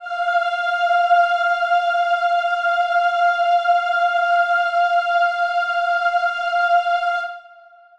Choir Piano
F5.wav